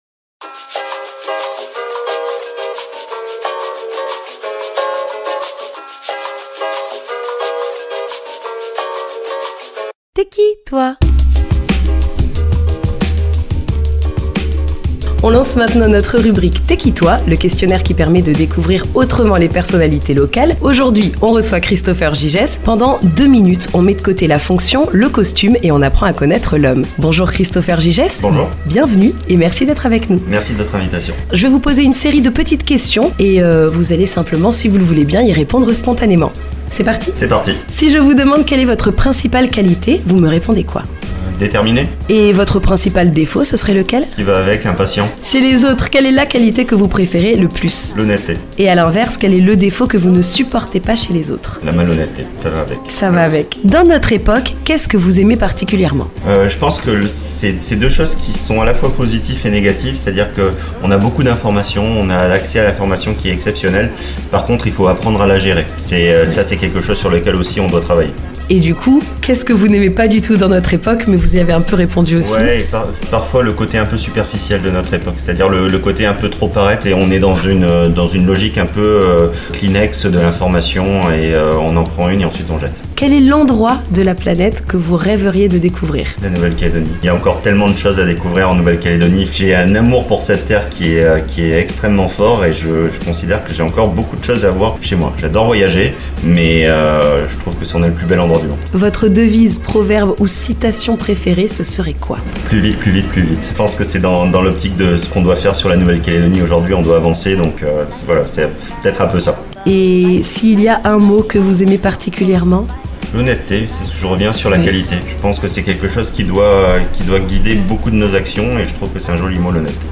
Pour cet épisode, nous recevons Christopher Gygès, membre du gouvernement de la Nouvelle-Calédonie. En deux minutes, il se prête au jeu des questions, entre spontanéité, réflexion et confidences.